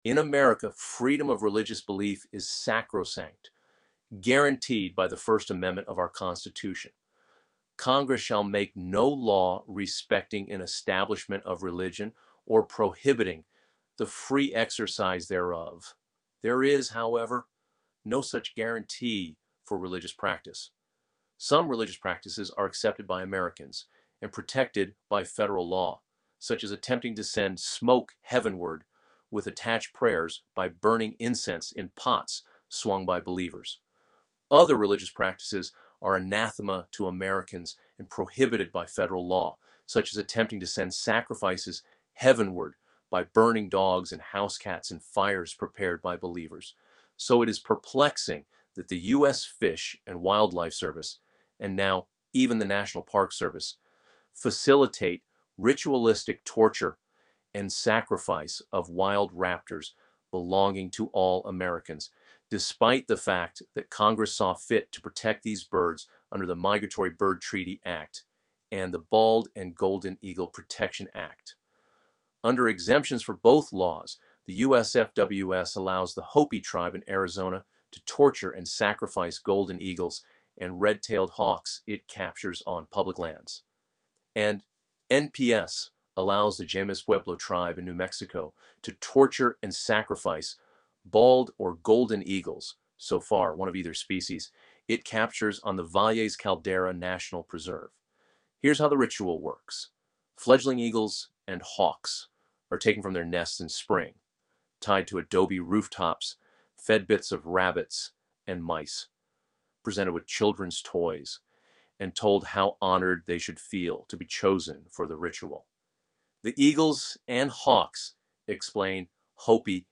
You can listen to an AI-generated reading of this story here: